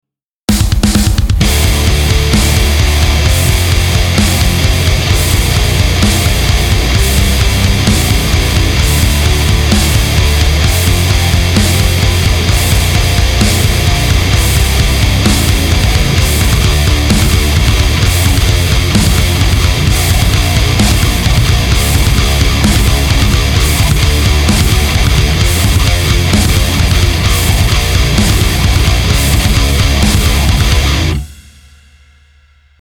Generic metalcore test mix
Hello, I'm new at recording myself at home (practicing for a few month now) today I decided to rerecord an old song I "wrote" few month ago. and I'm not fully satisfied with the final result, I don't think the guitar fit very well, I don't like my guitar tone. the guitars are not a lot...
And I've got a glitch around 28/29 seconds, I don't know how I did that...
PS: I didn't quantized or modified the audio tracks at all and I know I'm playing a little bit off tempo
the sound seems to be little bit clearer now but I still don't like the way it sounds, the drum kit sounds very compressed, the lack of precision at the beggining when I play chords)